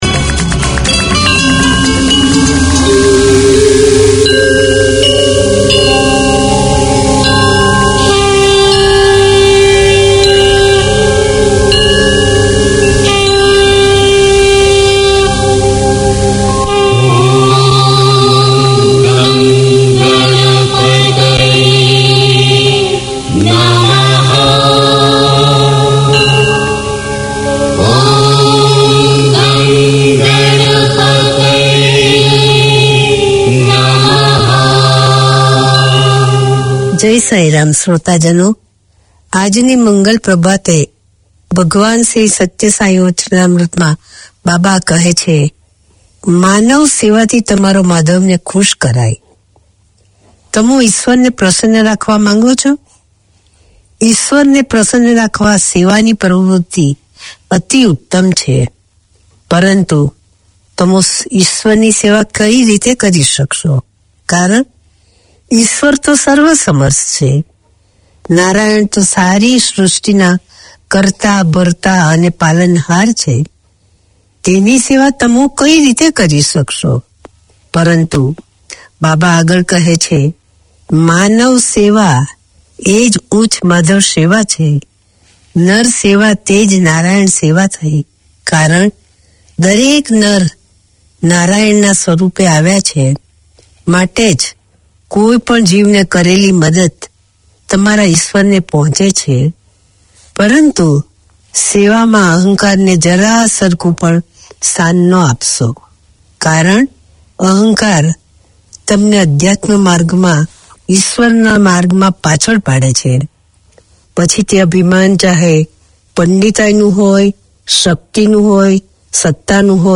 Each week Sai Baba devotees can pause for ten minutes to consider the teachings of Satya Sai Baba and hear devotional songs.